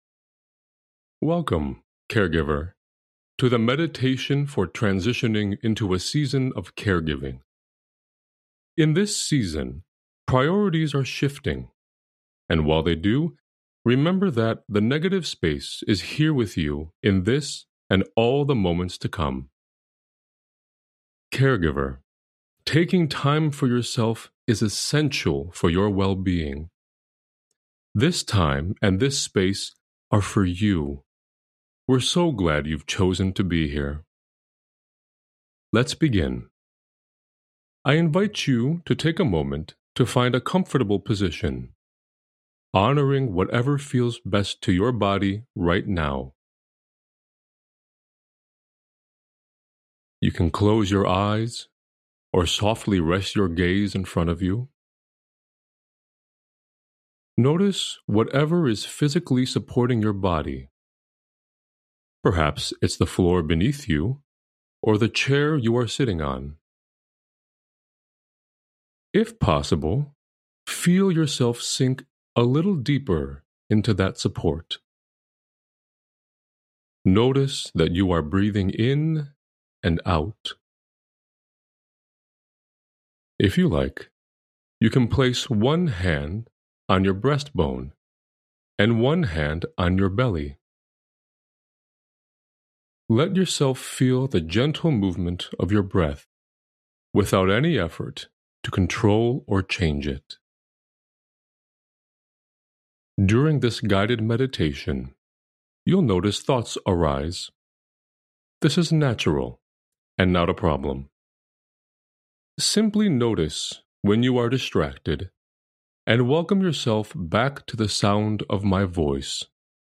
Guided Meditation for transitioning into a season of caregiving
TransitioningMeditation.mp3